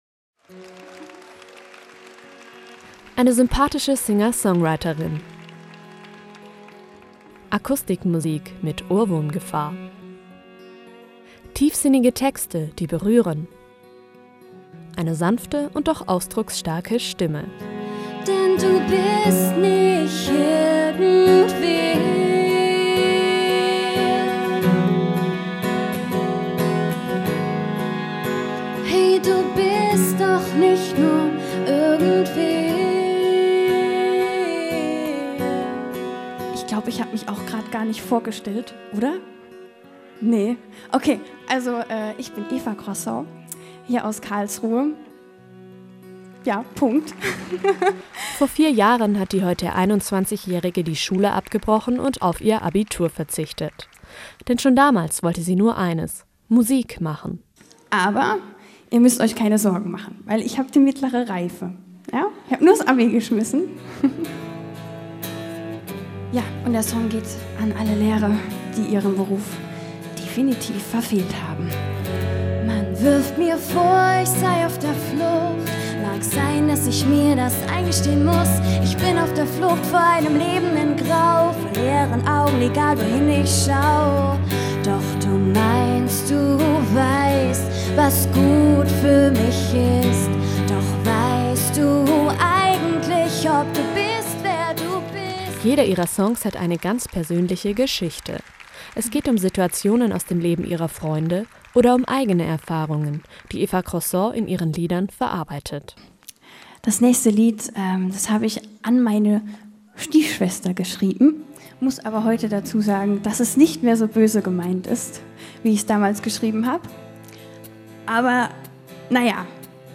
im Konzert